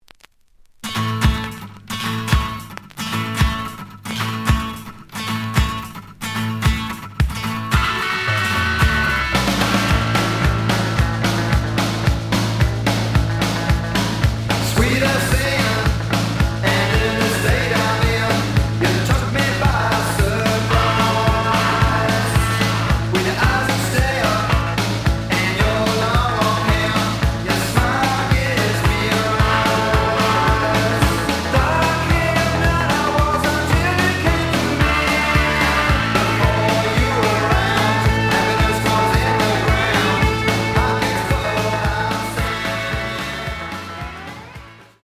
試聴は実際のレコードから録音しています。
The audio sample is recorded from the actual item.
●Format: 7 inch
●Genre: Rock / Pop